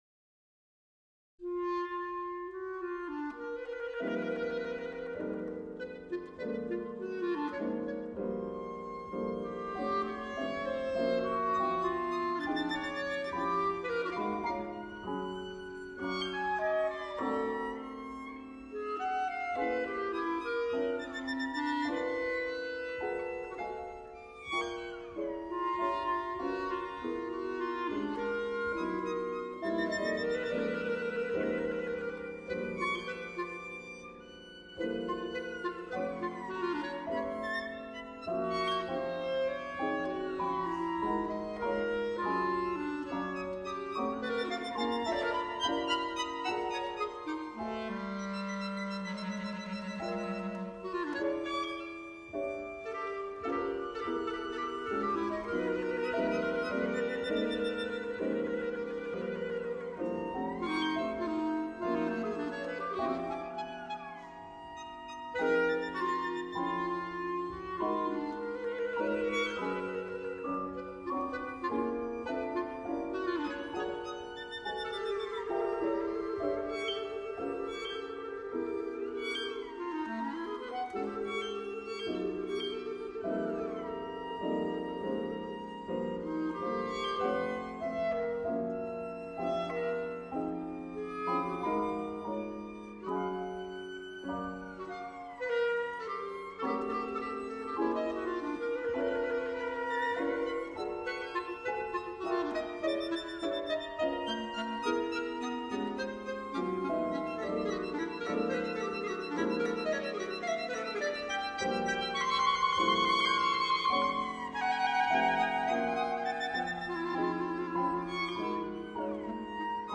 Violine
Violoncello
Klarinette